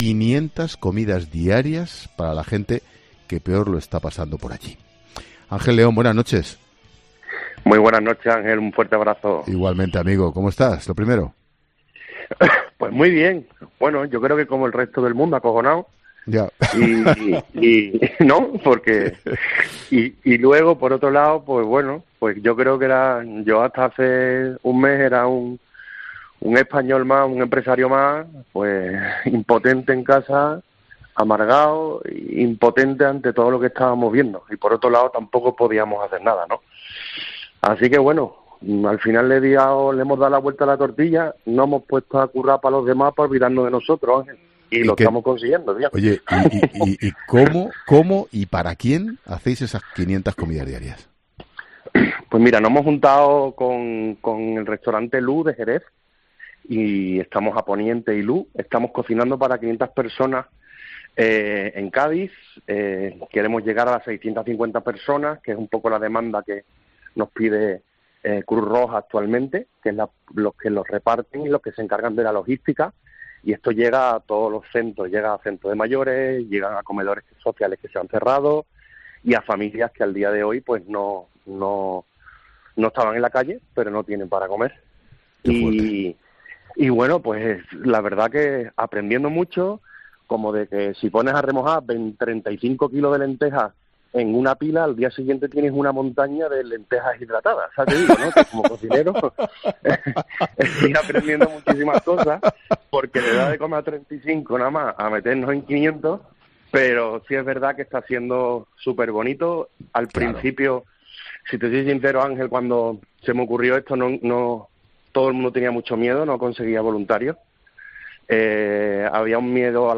León termina la entrevista mandando un mensaje de optimismo.